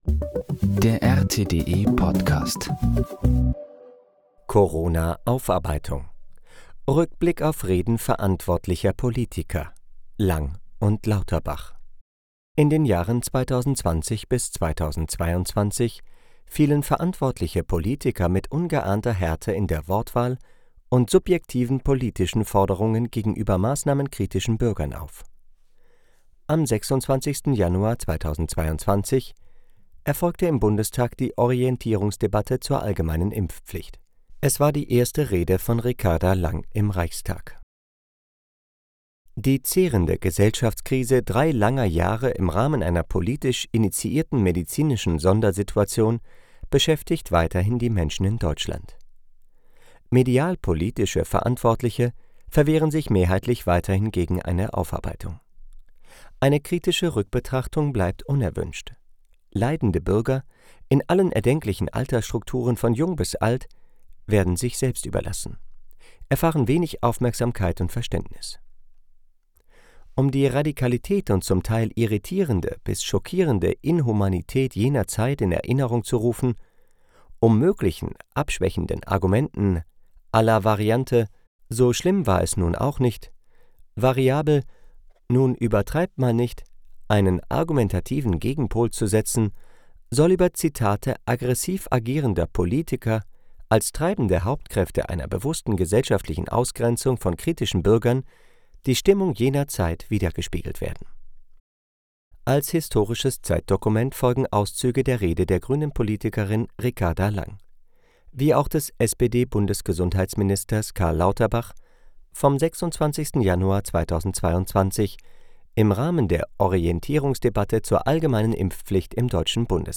Corona-Aufarbeitung: Rückblick auf Reden verantwortlicher Politiker – Lang und Lauterbach